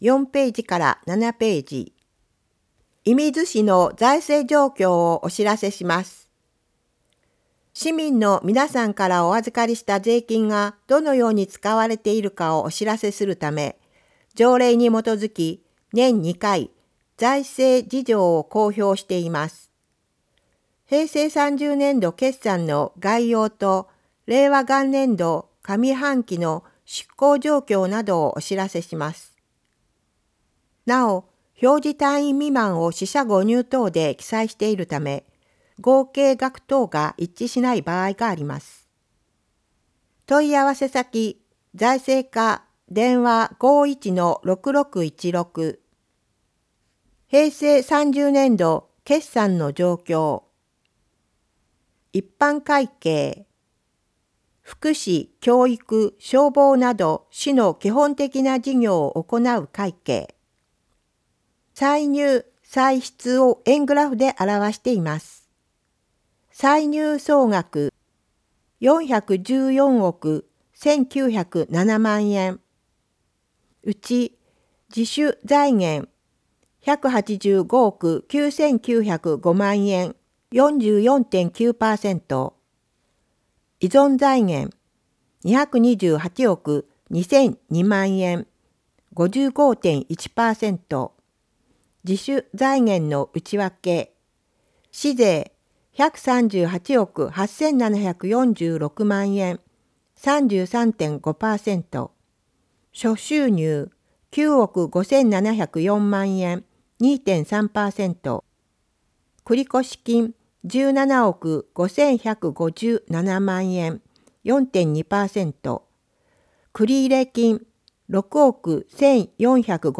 広報いみず 音訳版（令和元年12月号）｜射水市